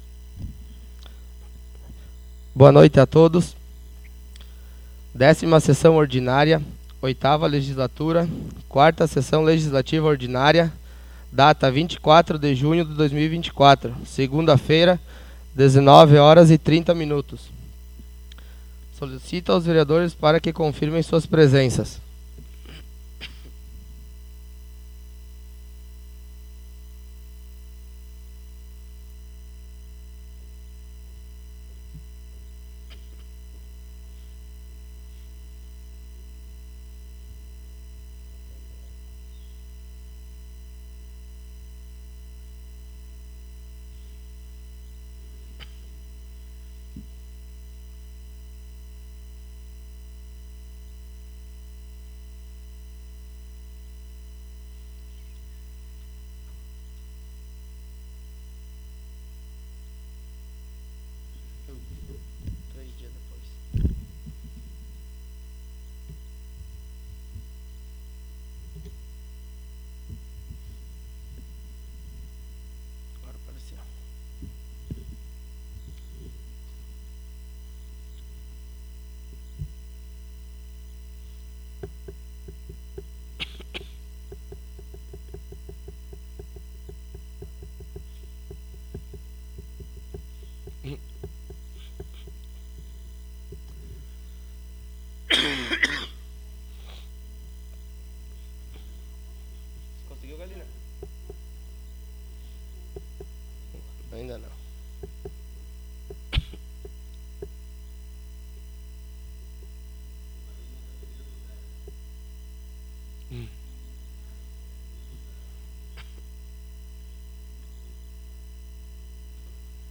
Audio da 10ª Sessão Ordinária 24.06.24